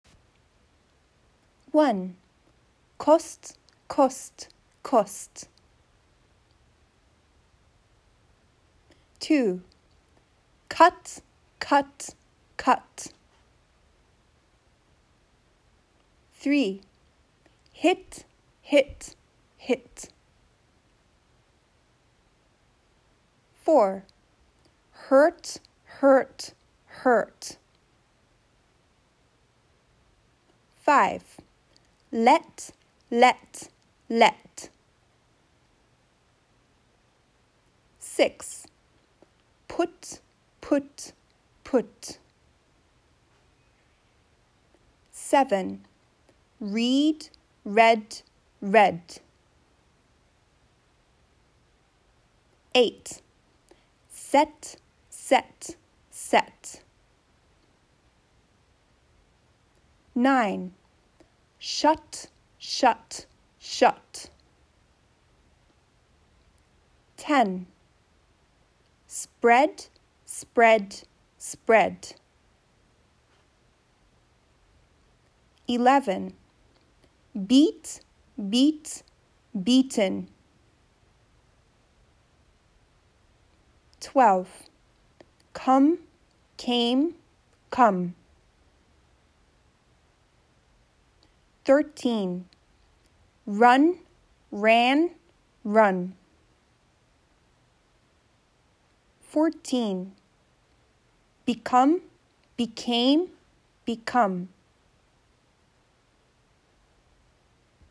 覚えるコツは、何度も聞いて、後に続けて練習することです。